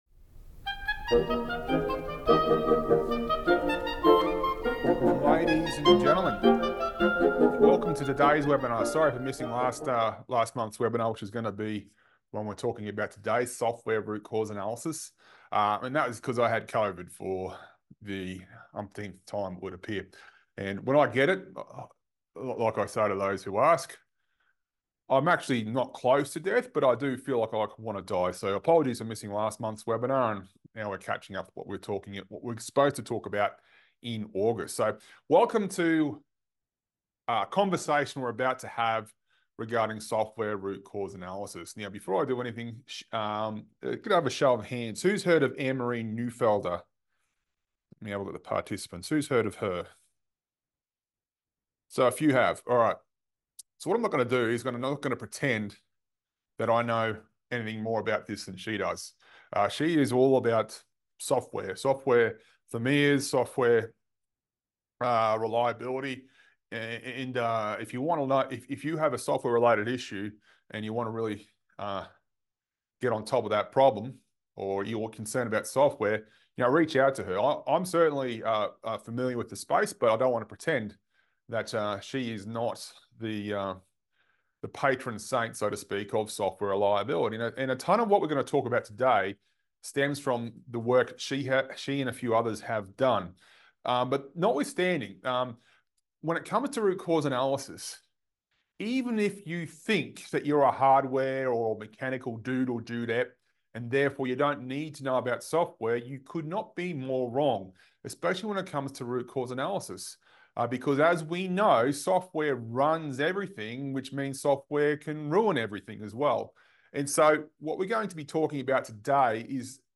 Which means we can work out what went wrong to allow that bug to happen. And that means we can quickly and efficiently improve software reliability! This Accendo Reliability webinar was originally broadcast on 23 September 2025.